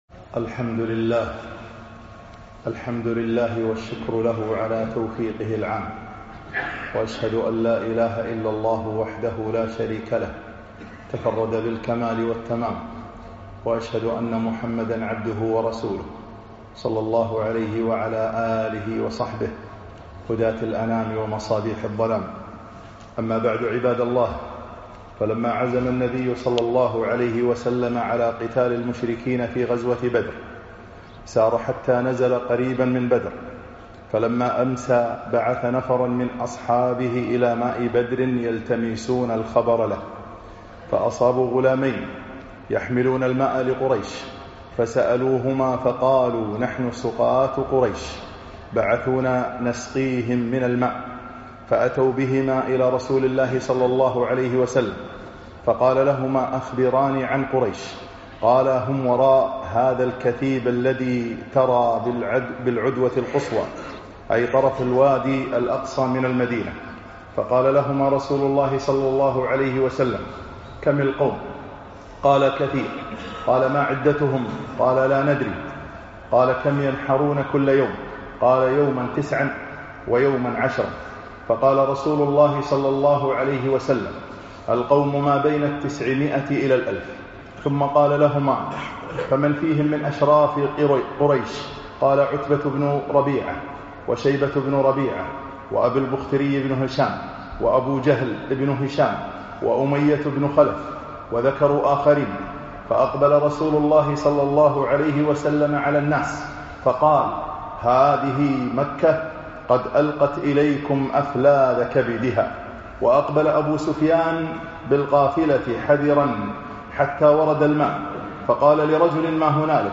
خطب السيرة النبوية 13